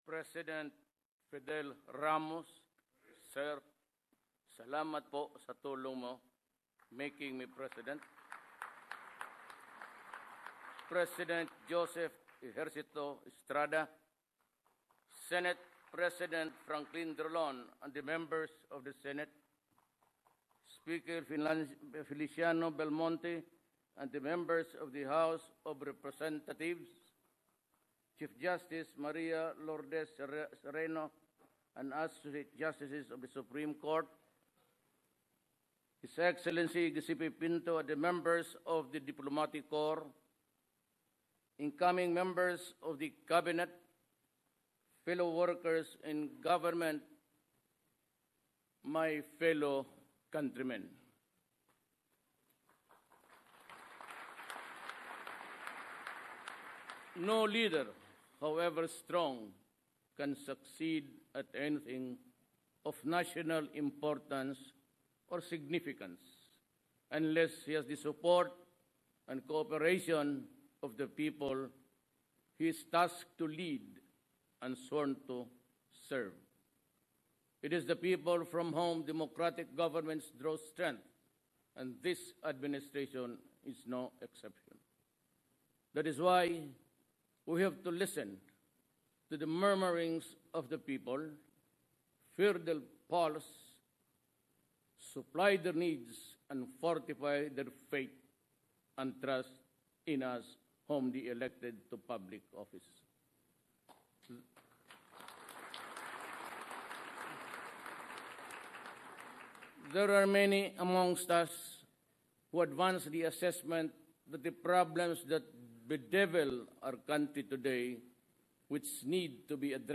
Narito ang kumpletong talumpati ni Pangulong Rodrigo R. Duterte, 16th na pangulo ng Republika ng Pilipinas, sa kanyang inagurasyon sa Malacañan Palace Larawan: Pangulong Rodrigo Duterte (Rappler)